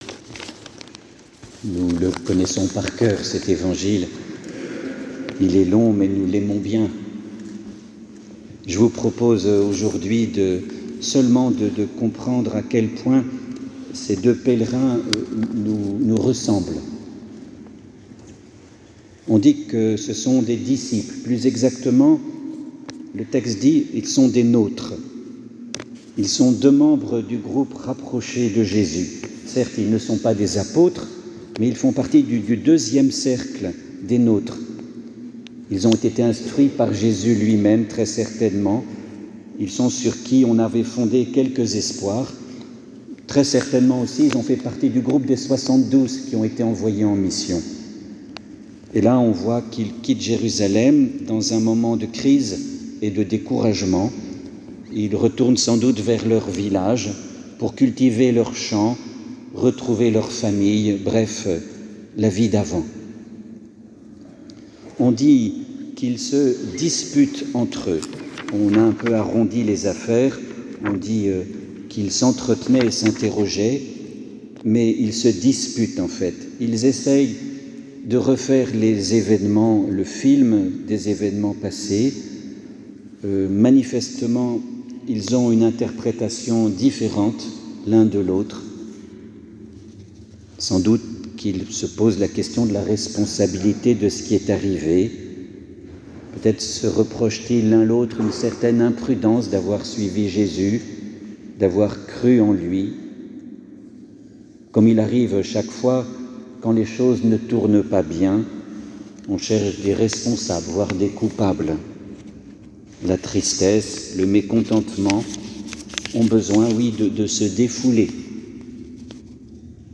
Homelie-Emmaus.mp3